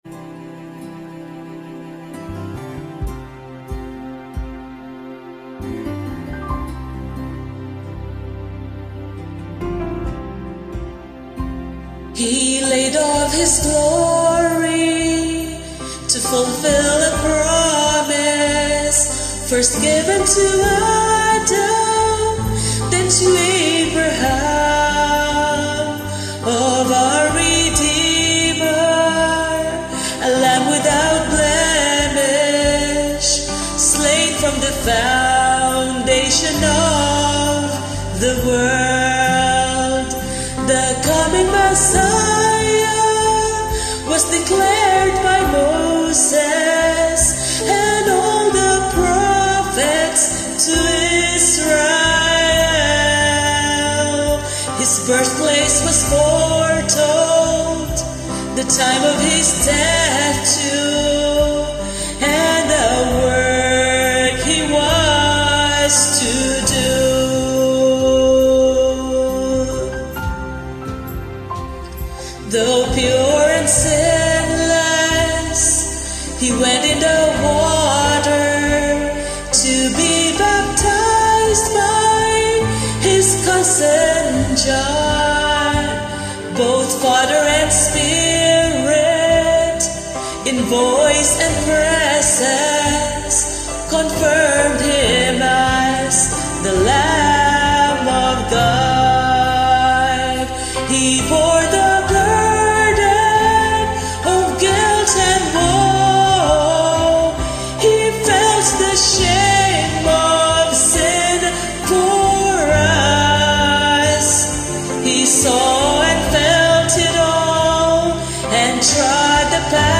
Hymns and Praise